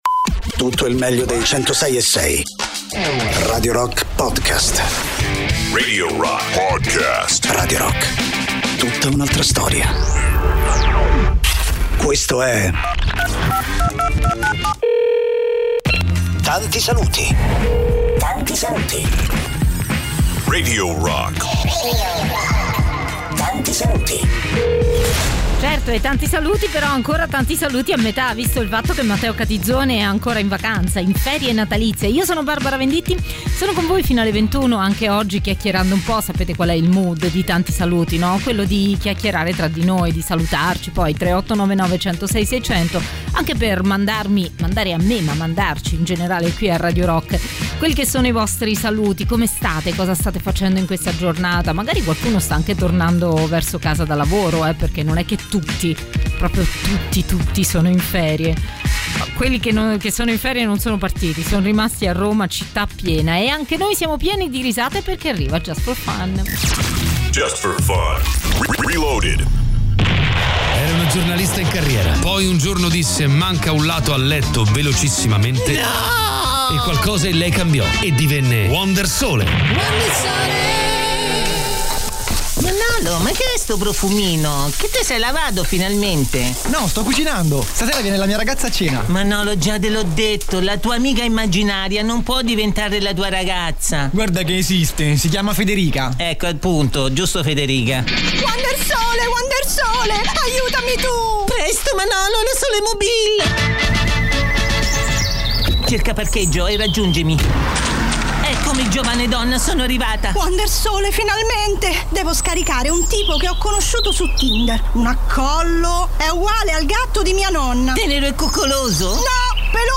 in diretta dal lunedì al venerdì, dalle 19 alle 21, con “Tanti Saluti” sui 106.6 di Radio Rock.